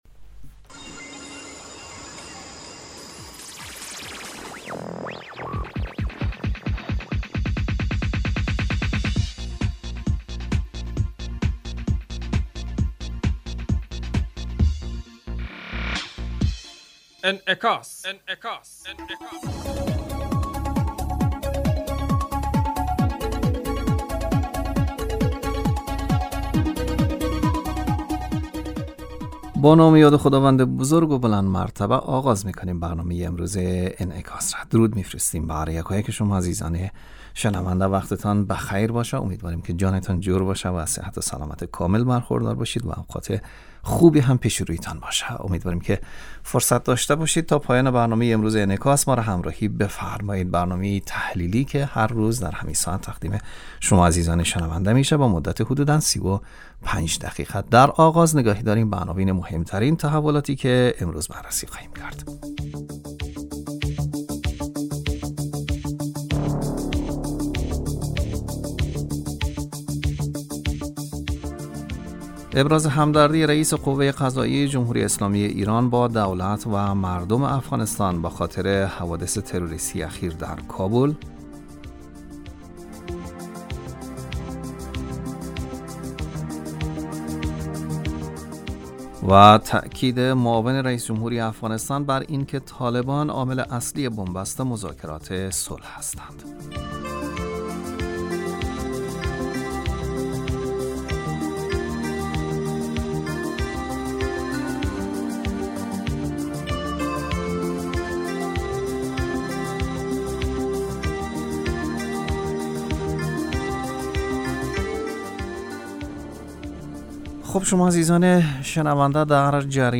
برنامه انعکاس به مدت 35 دقیقه هر روز در ساعت 12:00 ظهر (به وقت افغانستان) بصورت زنده پخش می شود.